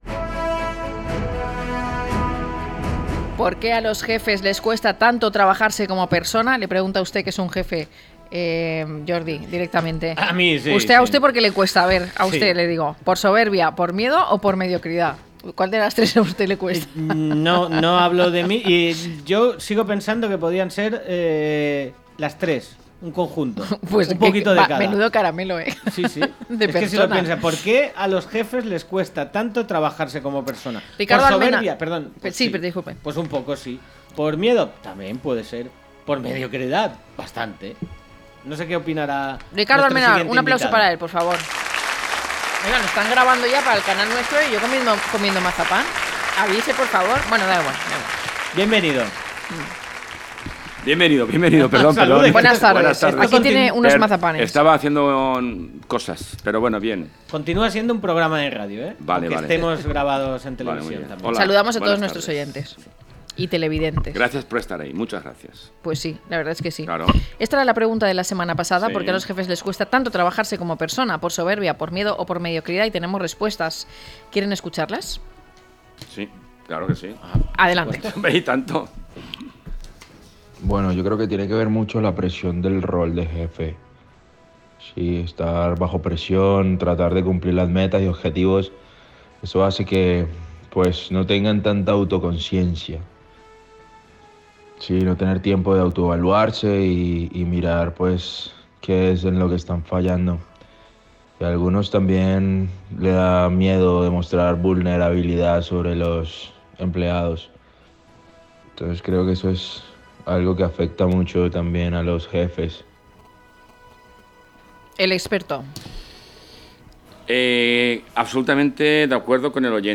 Nuestros oyentes responden a la pregunta que planteó el experto la semana pasada: ¿Por qué a los jefes les cuesta tanto trabajarse como persona? ¿Puede que sea por miedo, soberbia o mediocridad?